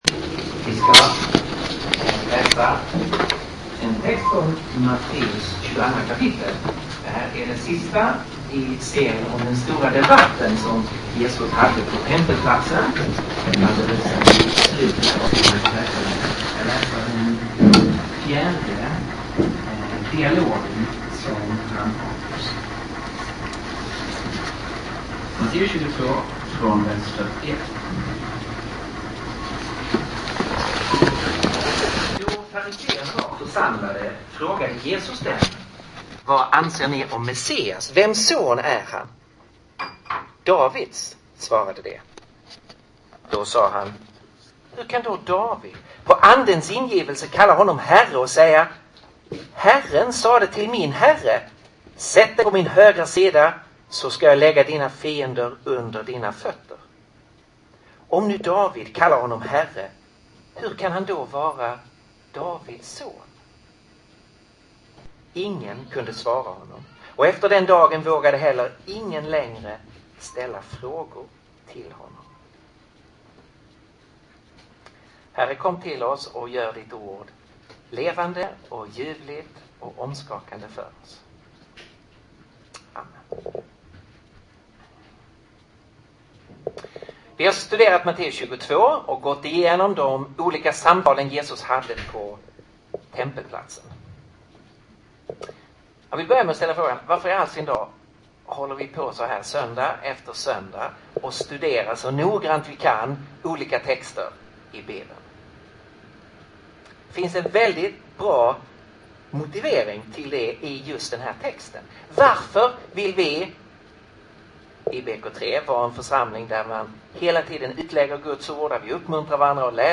Inspelad i BK3, Stockholm 2010-01-10.